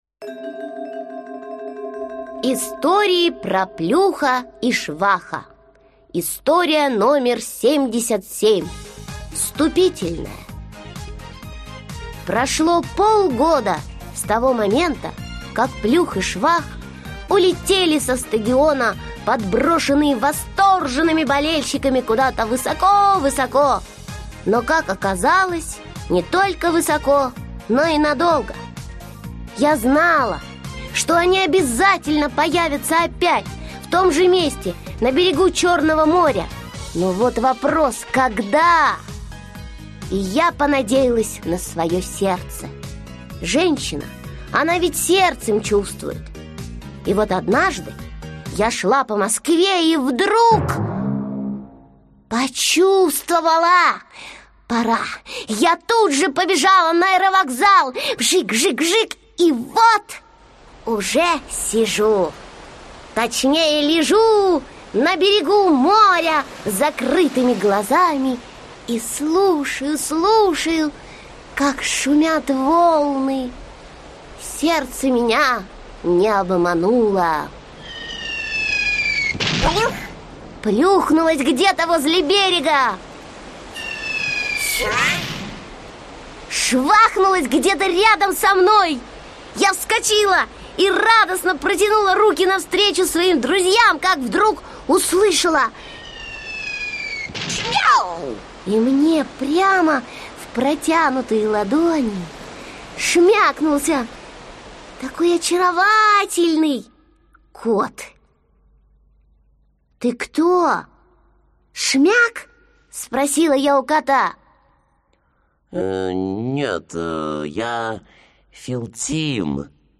Аудиокнига Планета кошек | Библиотека аудиокниг